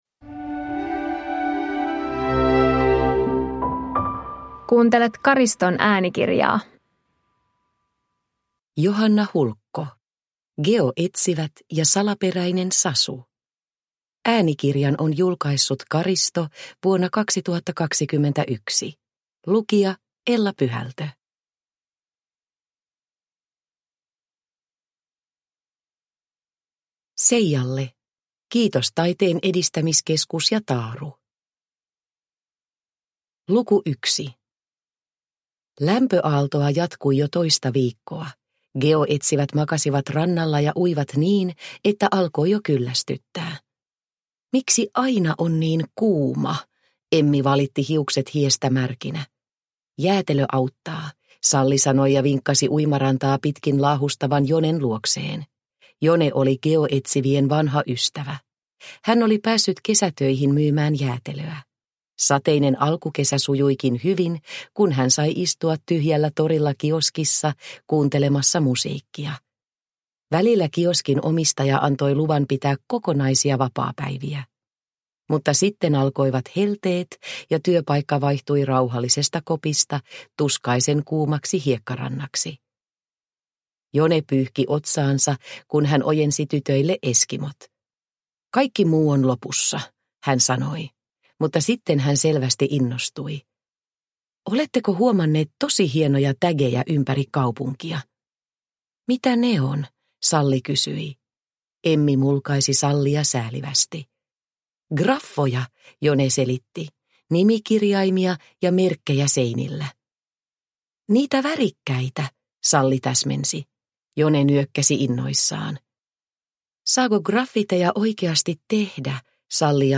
Geoetsivät ja salaperäinen Sasu – Ljudbok – Laddas ner